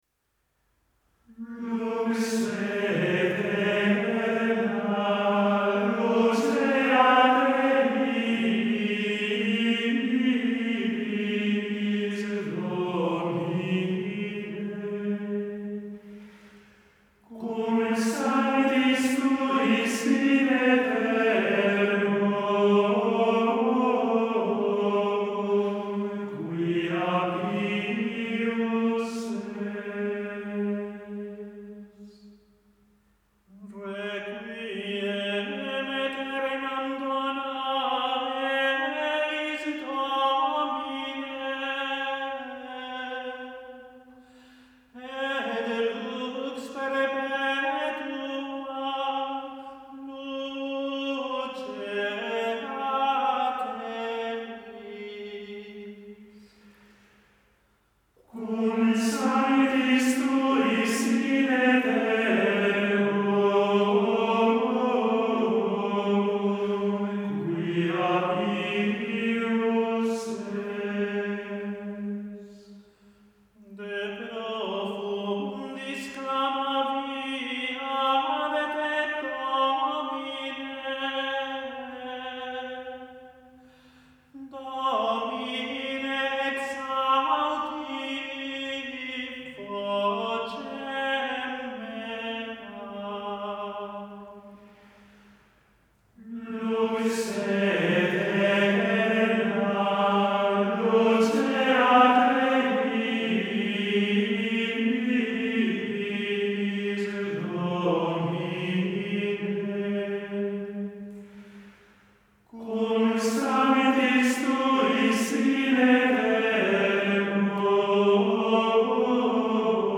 Lux aeternam. Comunión. Modo VII. Interpretes: Schola Antiqua.